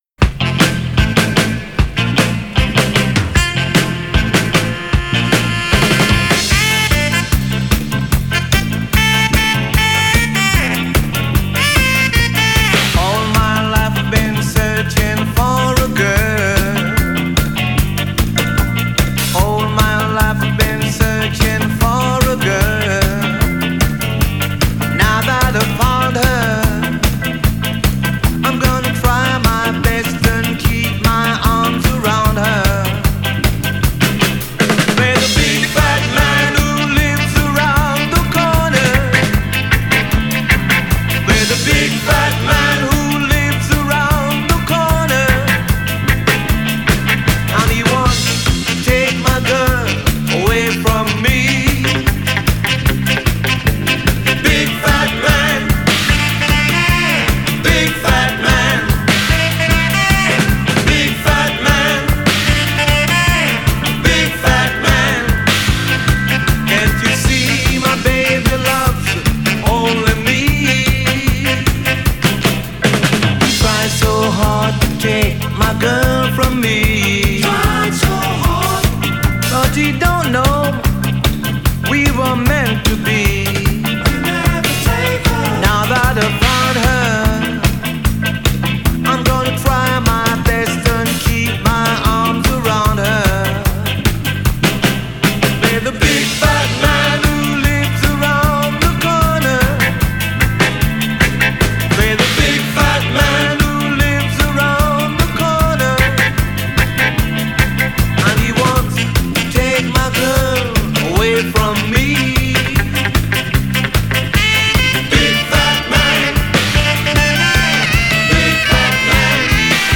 Genre: Ska, Two-Tone, New Wave, Reggae